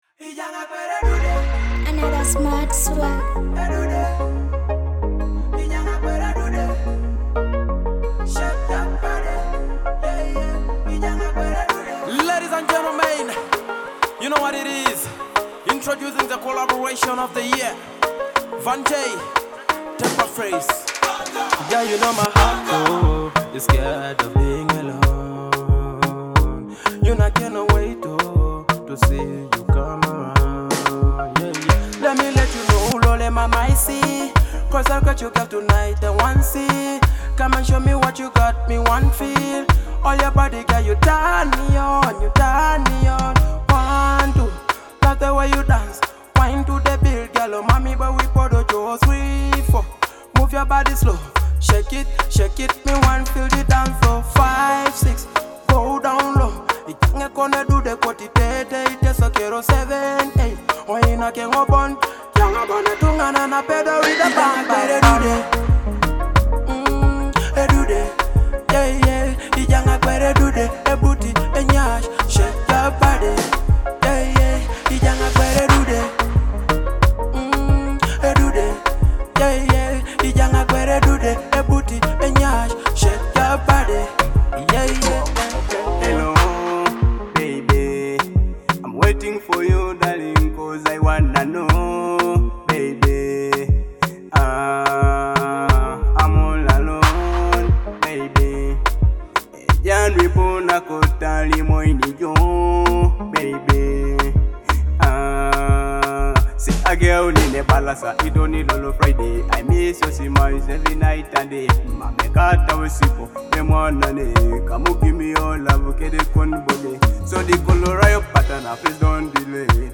is a vibrant Afrobeat x Dancehall hit packed with energy.